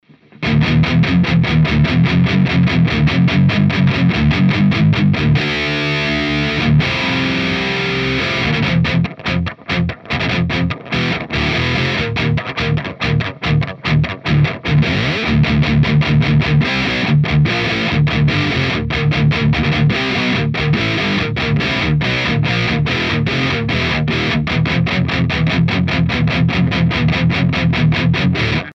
Metal Gitarren - Out of the Box - Wie selektiert ihr den "Grundsound" ?
Alle Beispiele sind leider aus der Dose. Völlig unbearbeitet.
( auf mich alles dumpf wirkend ) Nach welchen Kriterien sucht ihr euren "Grundsound" für Metalkompositionen aus ?